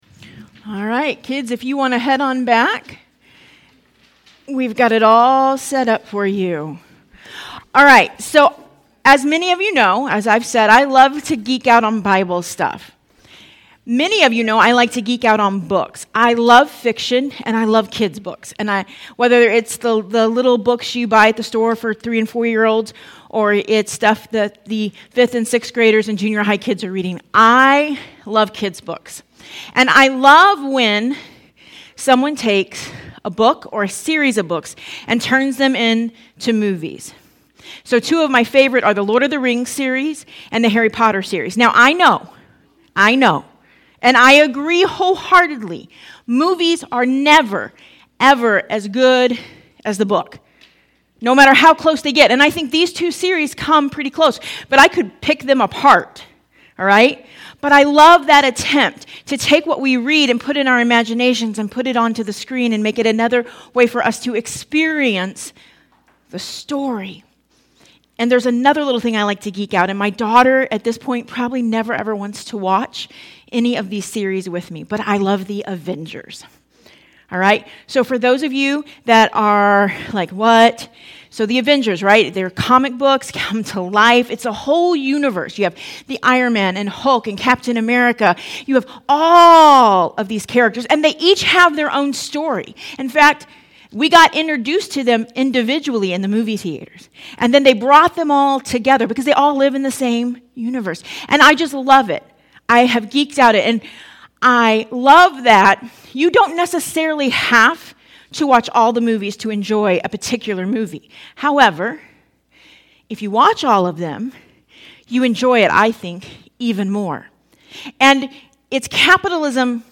Sermons | Compassion Church